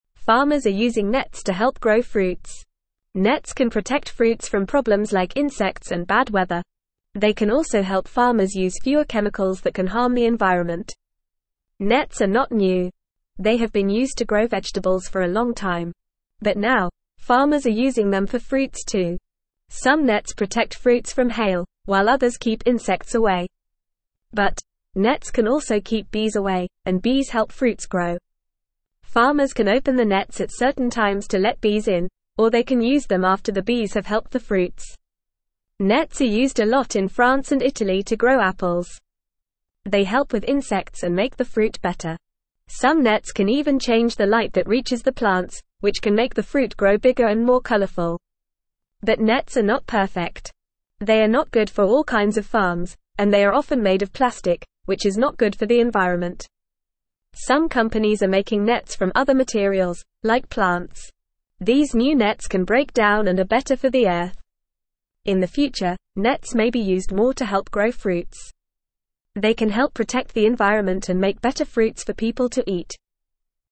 English-Newsroom-Beginner-FAST-Reading-Farmers-Use-Nets-for-Growing-Fruits.mp3